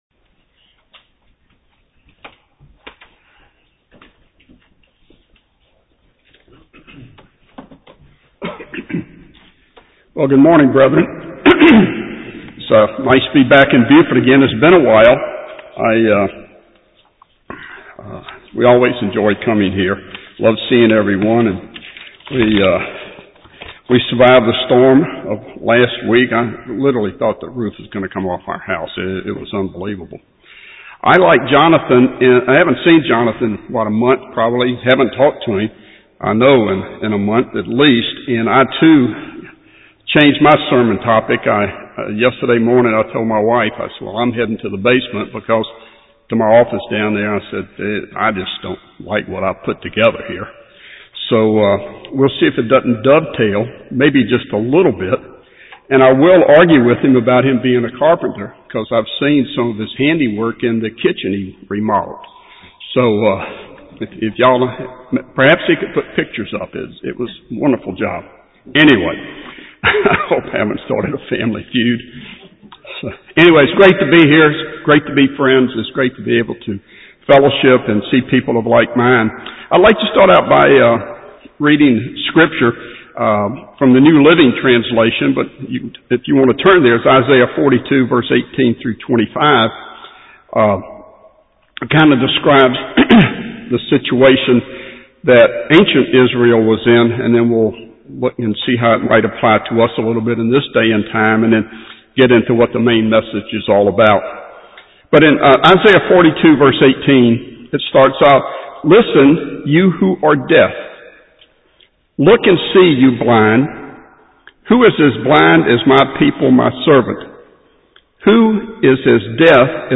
Given in Buford, GA
UCG Sermon Studying the bible?